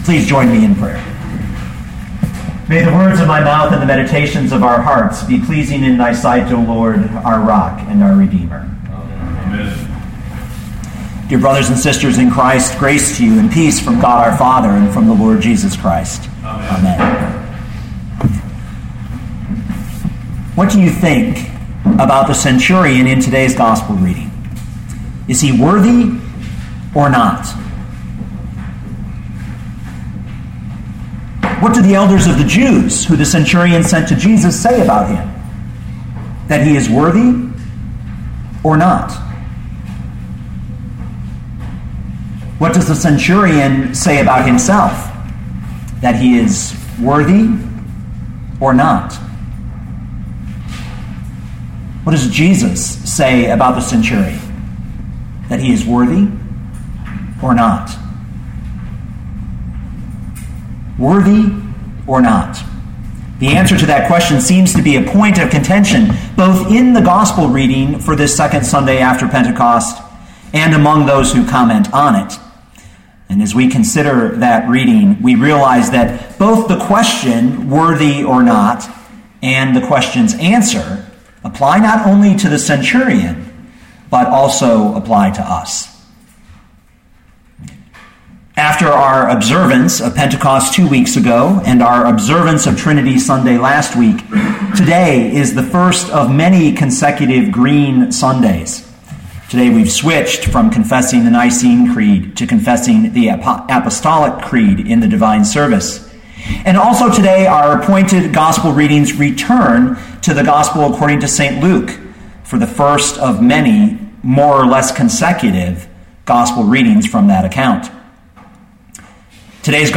2013 Luke 7:1-10 Listen to the sermon with the player below, or, download the audio.